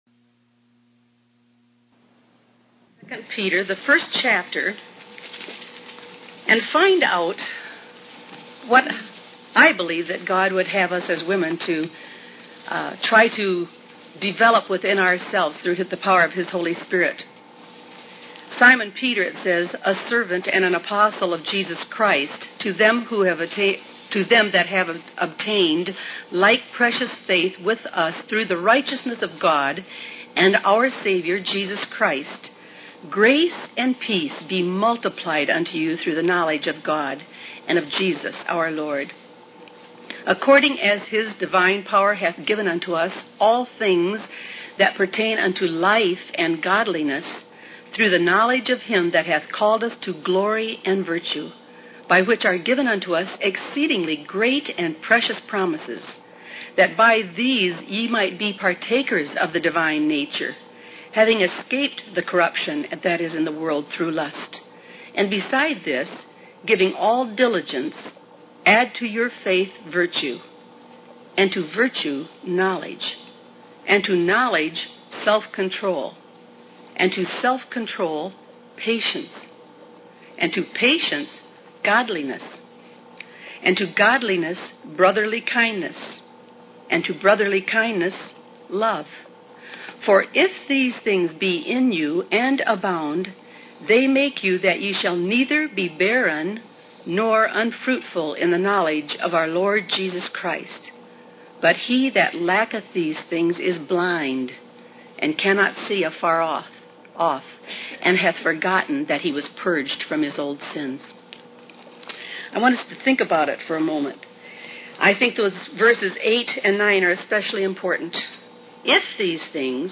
In this sermon, the speaker emphasizes the importance of having certain virtues in our lives to bear fruit in the knowledge of Jesus Christ. The scripture warns that lacking these virtues makes us blind and forgetful of what God has done for us.